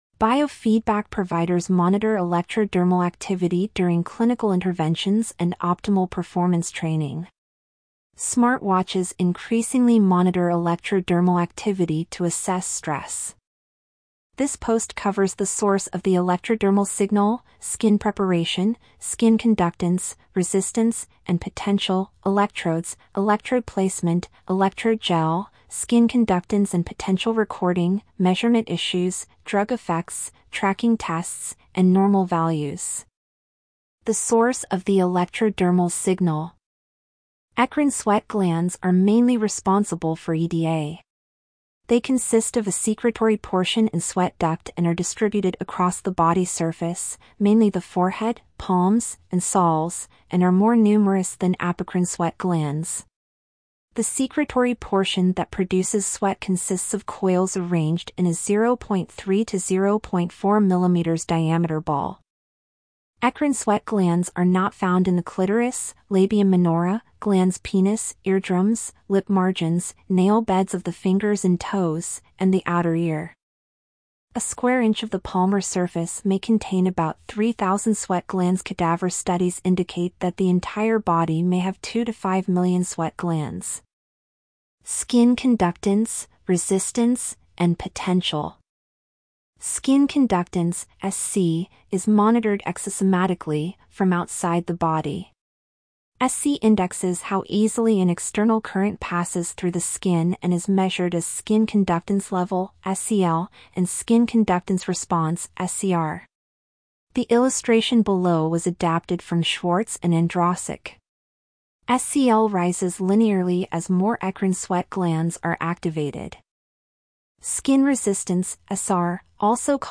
Click on our narrator icon to listen to this post.